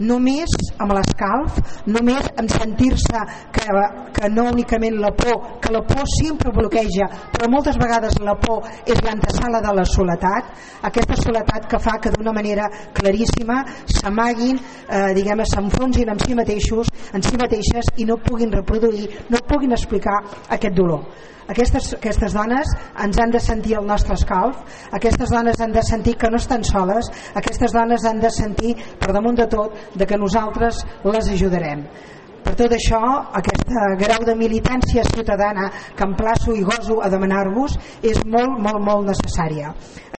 Calella va commemorar ahir el Dia Internacional per a l’Eliminació de la Violència envers les Dones amb un acte a la plaça de l’Església, on es van enlairar globus per recordar a totes les dones que han mort a mans de les seves parelles durant aquest 2015.
Recordava l’alcaldessa de Calella durant la seva intervenció en l’acte que cap de les sis dones mortes per aquesta xacra a Catalunya havia denunciat abans haver rebut mals tractes. Montserrat Candini va apel·lar a tenir una “militància cívica” per combatre la violència masclista que, deia, passa per estar amatents al que succeeix al nostre voltant i, en la mesura del possible, fer que les dones que les pateixen no se sentin soles.